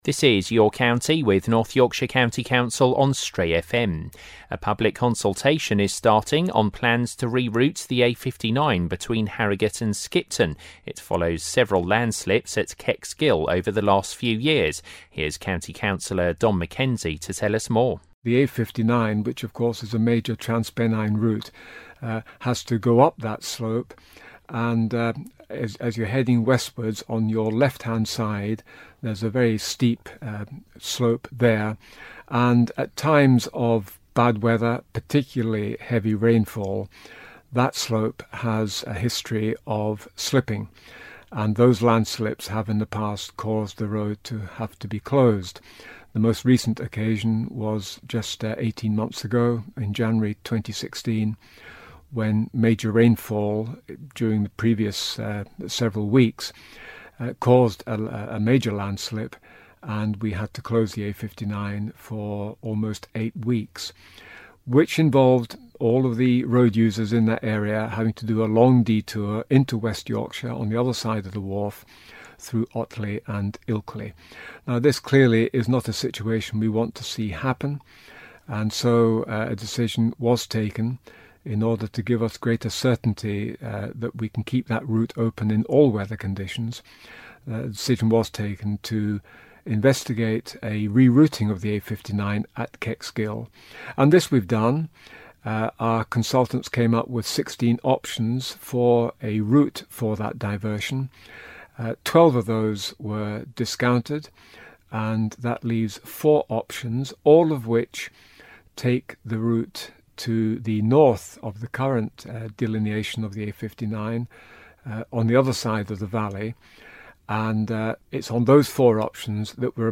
County Councillor Don Mackenzie tells Stray FM about plans to reroute the road following several landslips.